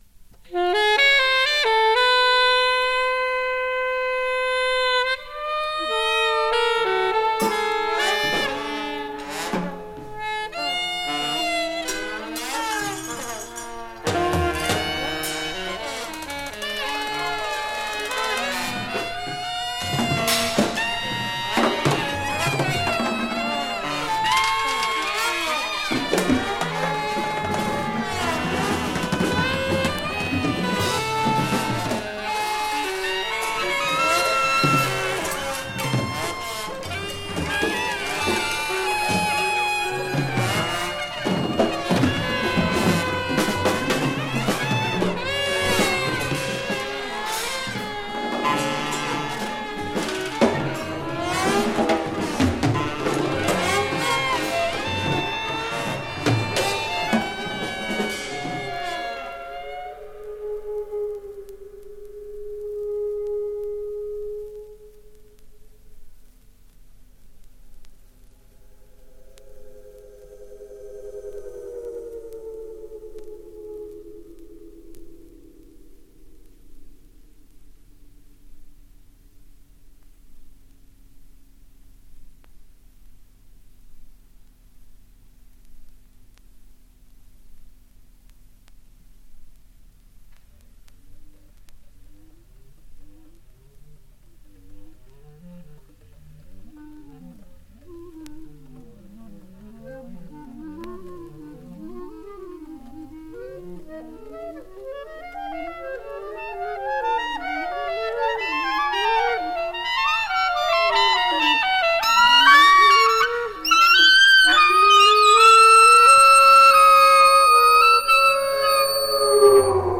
副題に＜4chによる打楽器とオーケストラのためのコンポジション＞と銘打った意欲作です。
1971 Format LP Notes 4 Channel Stereo